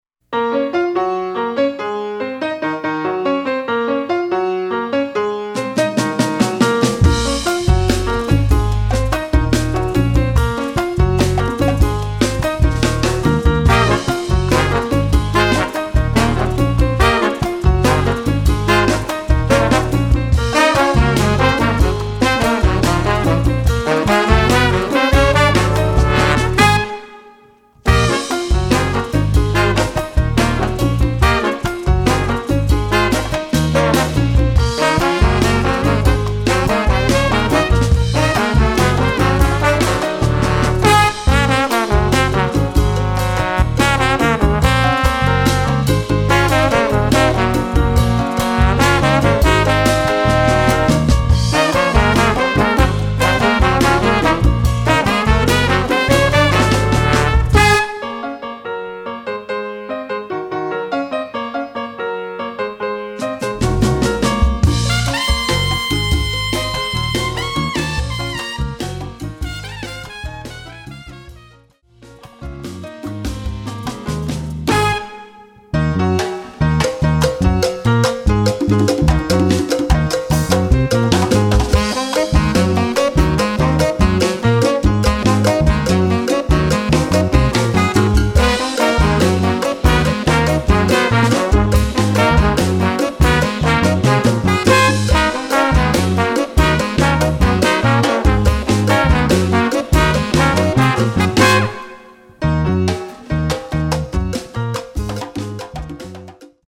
Category: combo (septet)
Style: funky son